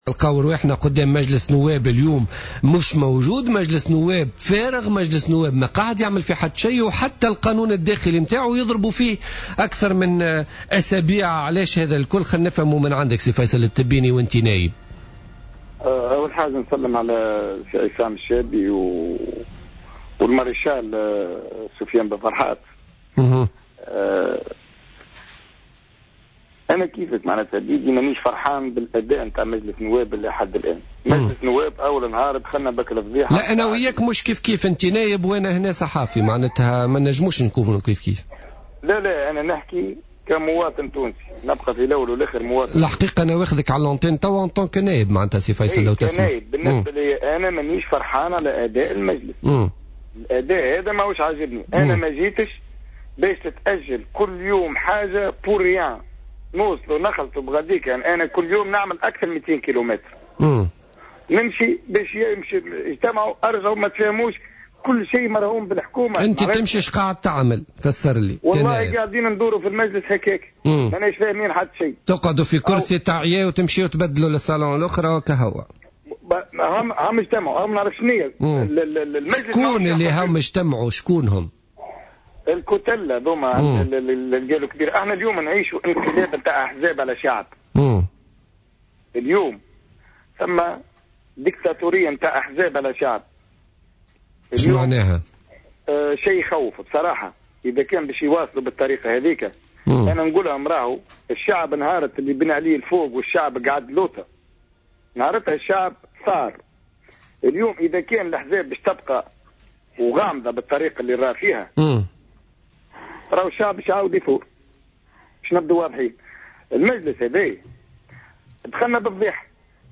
Faïcel Tebbini, député du parti la Voix des agriculteurs au parlement des représentants du peuple, est intervenu ce jeudi 29 janvier 2015 sur les ondes de Jawhara FM dans le cadre de l’émission Politica.